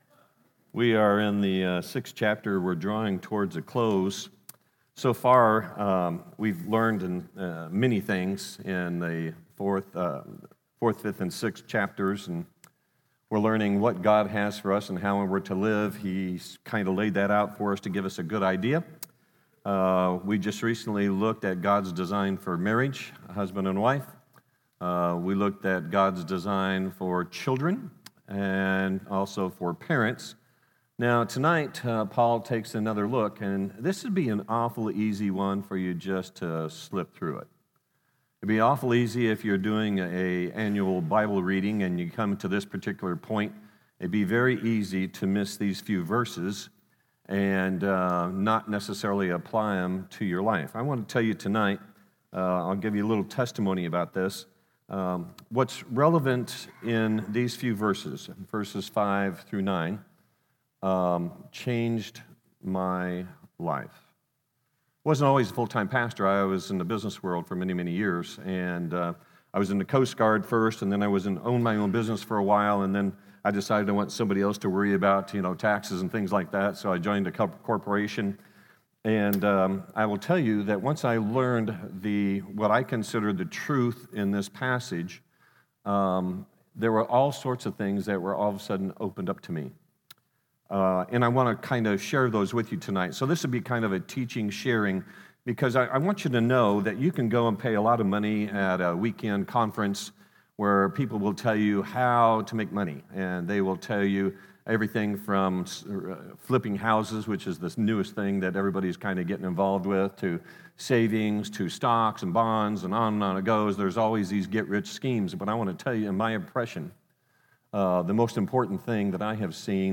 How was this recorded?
Living as the Church Ephesians Watch Listen Save Audio on the video is corrupt until minute mark 6:28.